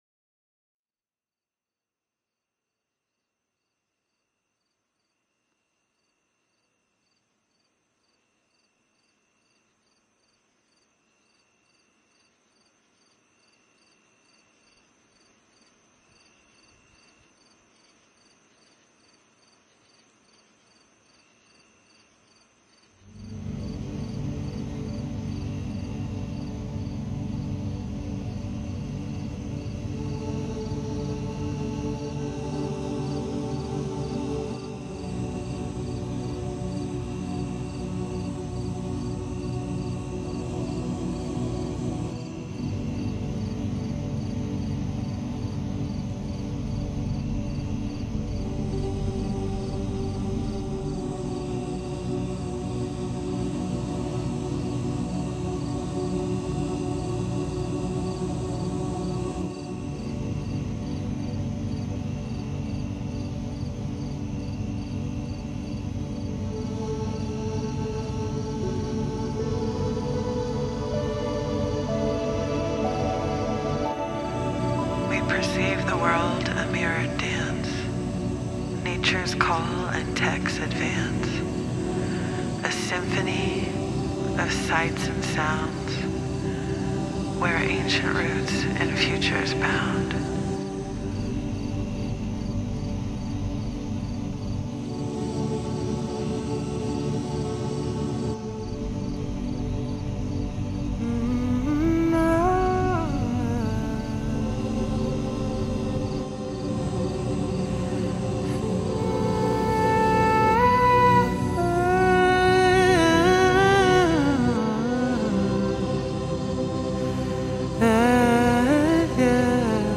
three songs of original music
cinematic-pop